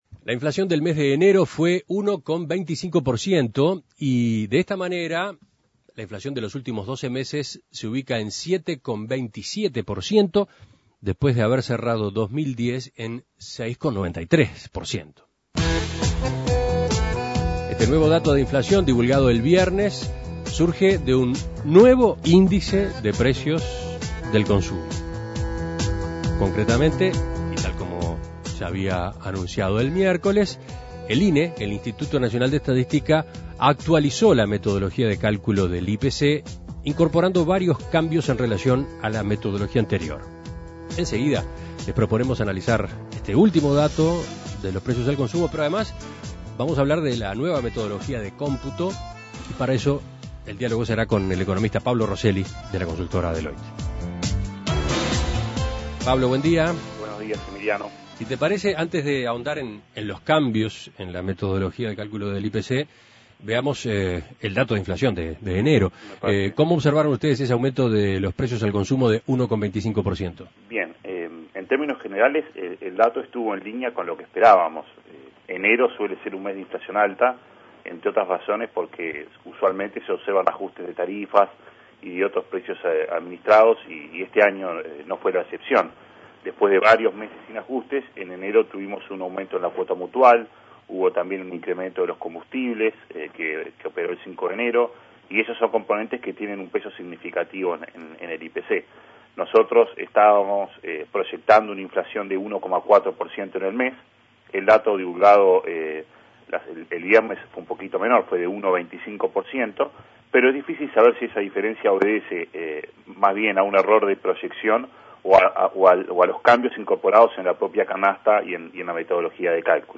Análisis Económico Los precios al consumo subieron 1,25% en enero.